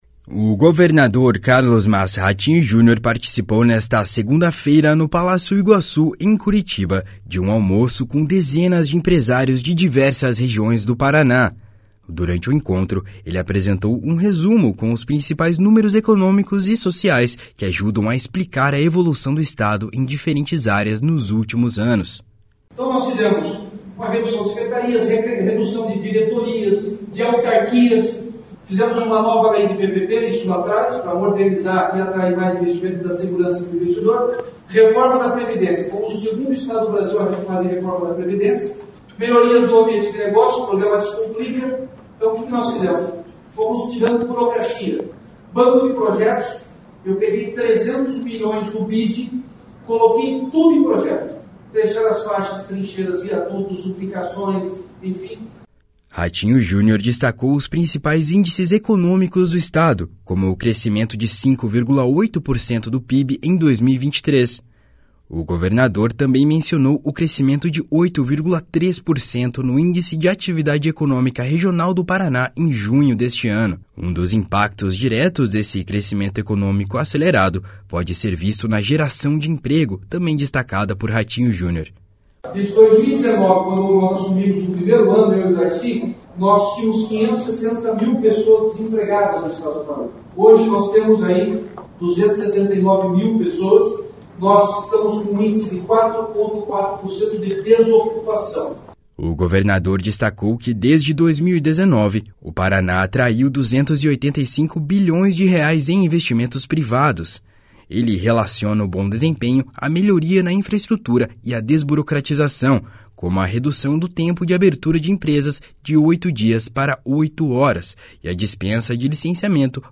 O governador Carlos Massa Ratinho Junior participou nesta segunda-feira no Palácio Iguaçu, em Curitiba, de um almoço com dezenas de empresários de diversas regiões do Paraná.
// SONORA RATINHO JUNIOR //